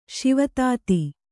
♪ Śiva tāti